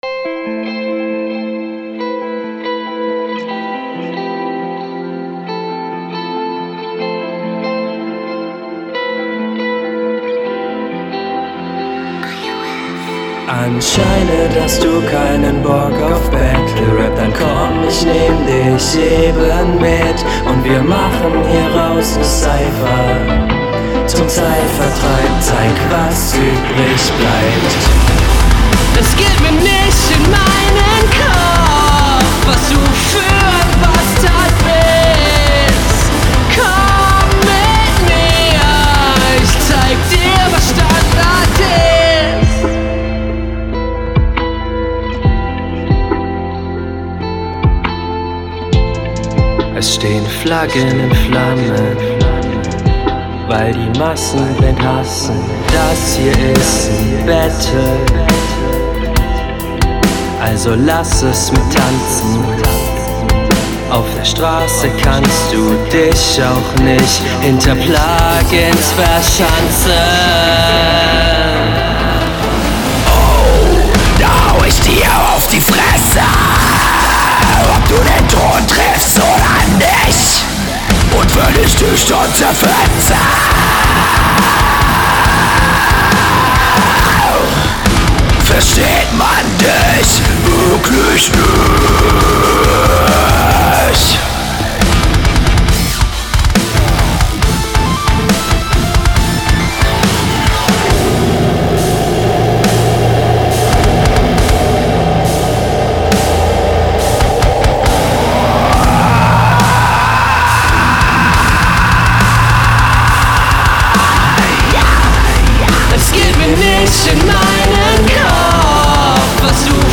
Flow: Du flowst sehr routiniert und abwechslungsreich auf dem Beat.
Das ist halt auch mehr nen Song auf nem Metalbeat.